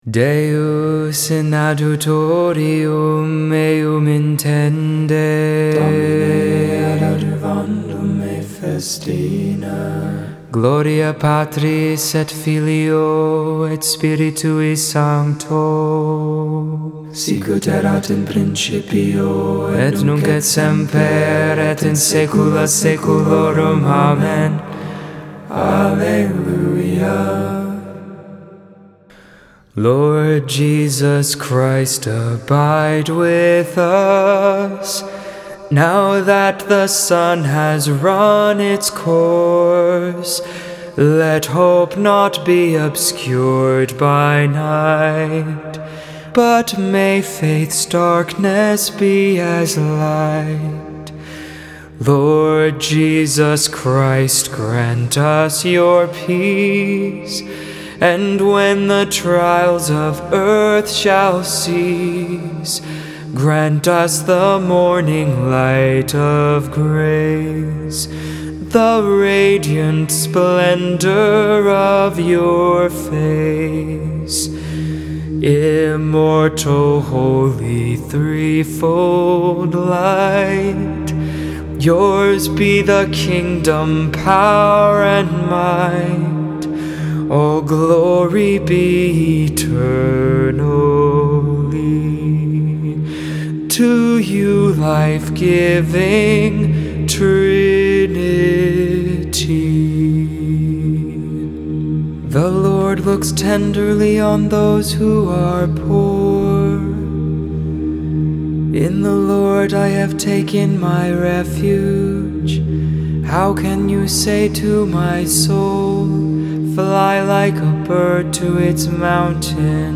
1.11.21 Vespers (1st Monday in Ordinary Time)